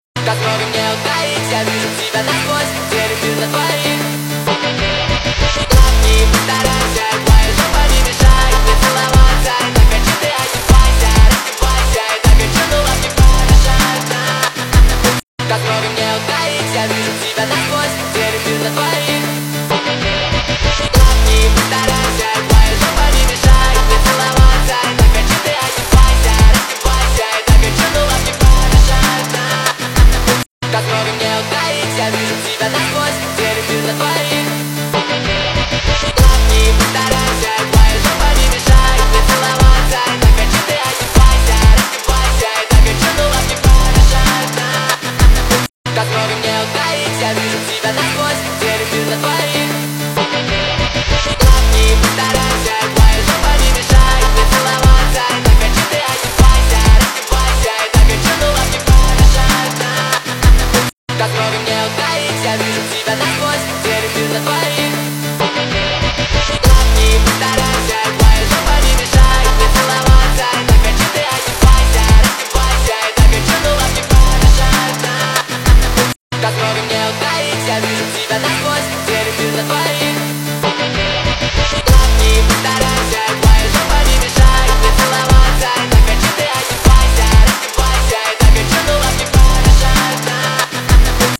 • Качество: 320 kbps, Stereo